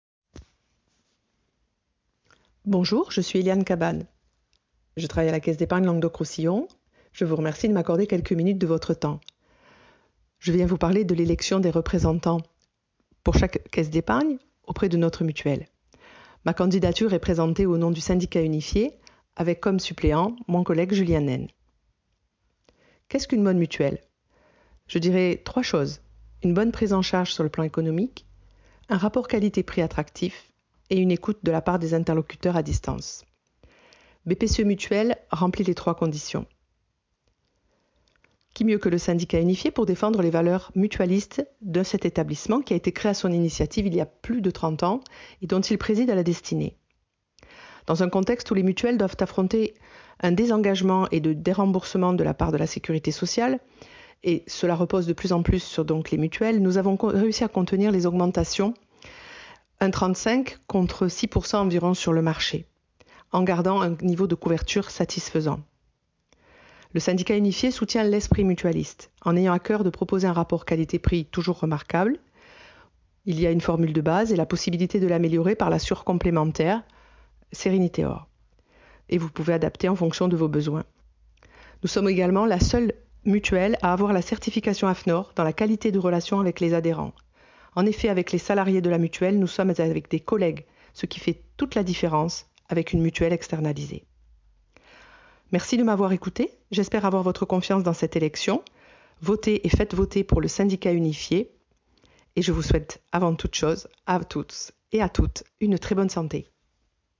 Témoignage EXCLUSIF 🎤🎶🎙